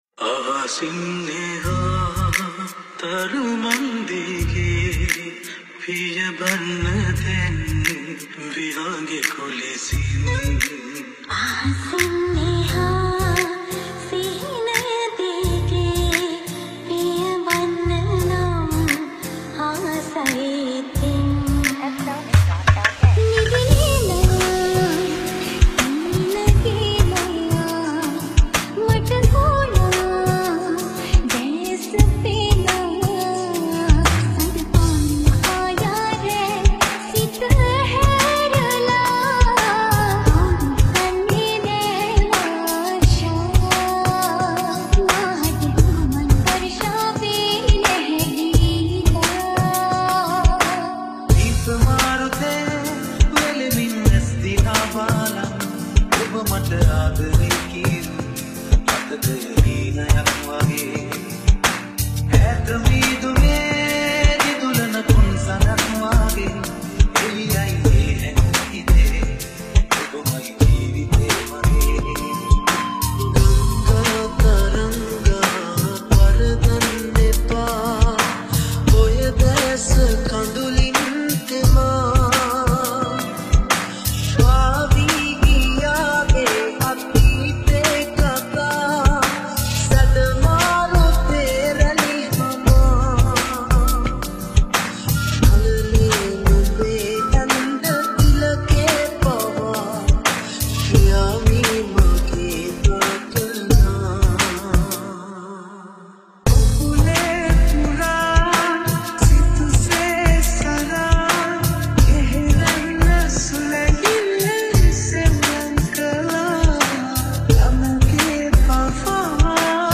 High quality Sri Lankan remix MP3 (6.5).